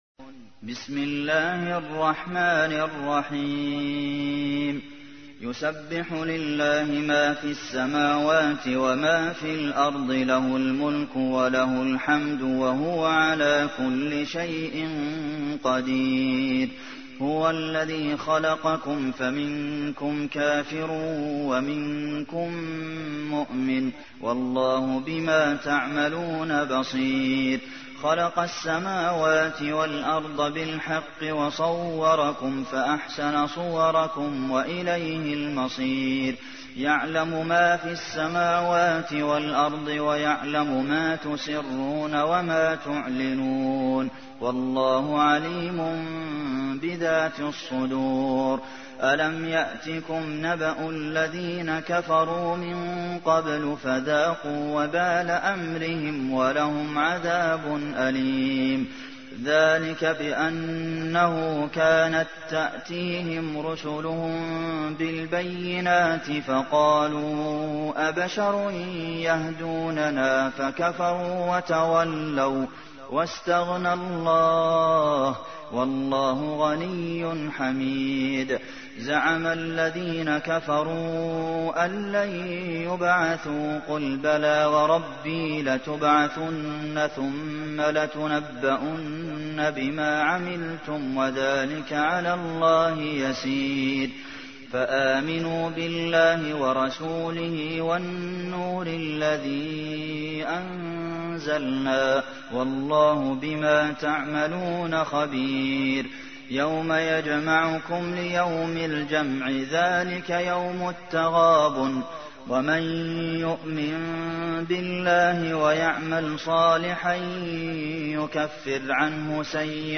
تحميل : 64. سورة التغابن / القارئ عبد المحسن قاسم / القرآن الكريم / موقع يا حسين